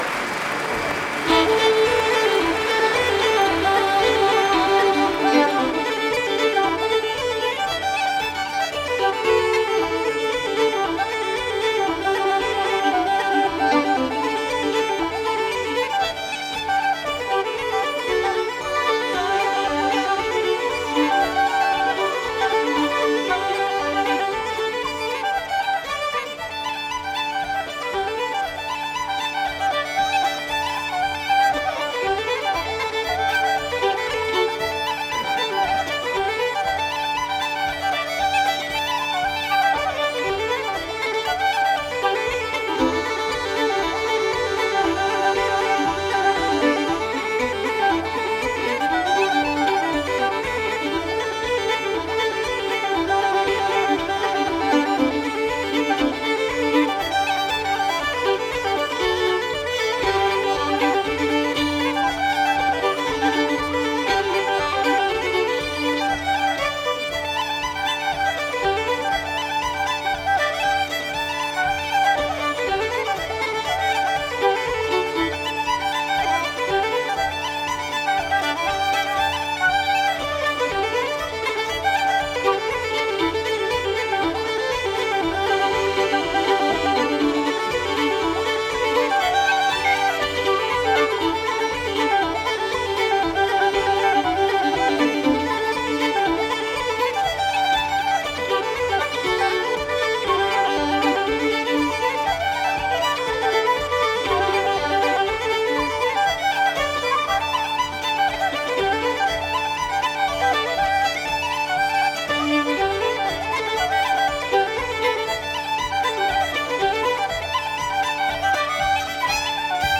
本专辑所包含的音乐比一般的爱尔兰民谣要古老得多。
风笛、小提琴等乐器逐渐发展出爱尔兰音乐的固有特色。
幸好，这是一张现场录音的演奏会唱片。